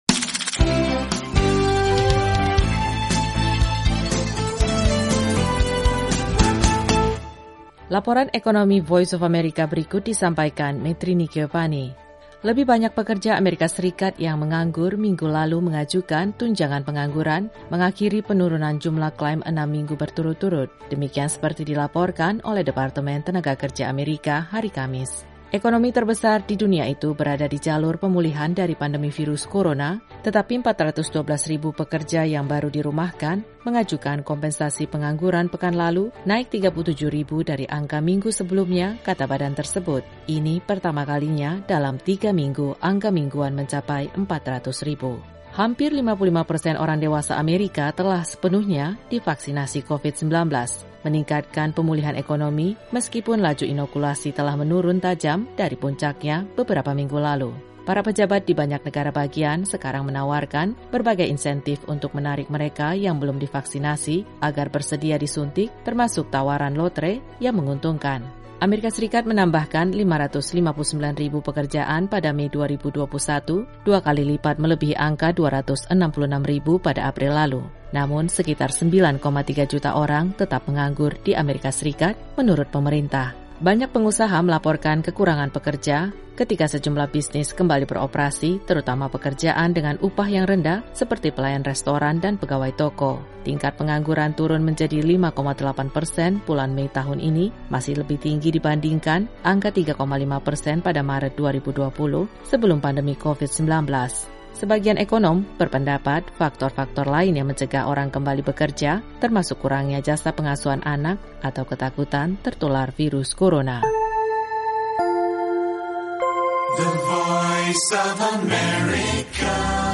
Laporan Ekonomi VOA hari ini mengenai klaim tunjangan pengangguran di AS naik sedikit pada pertengahan Juni 2021. Simak juga laporan terkait taman hiburan Italia yang dibuka kembali setelah berbulan-bulan ditutup.